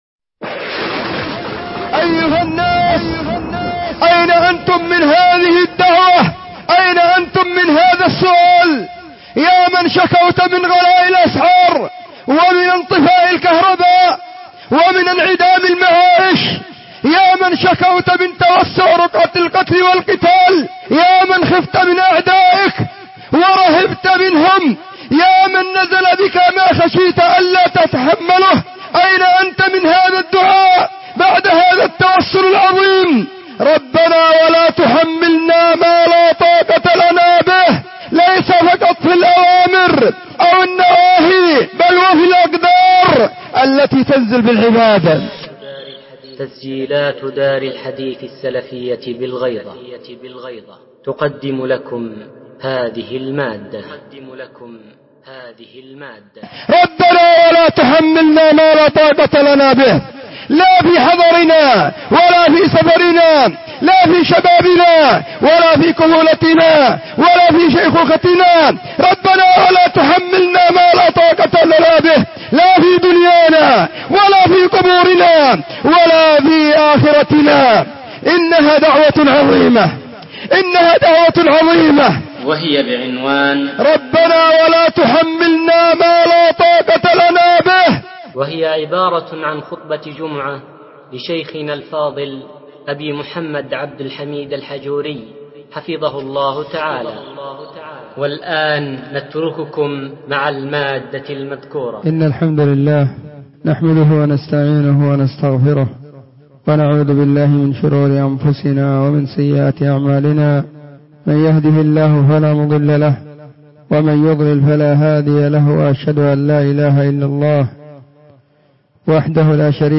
📢 وكانت في مسجد الصحابة بالغيضة محافظة المهرة – اليمن.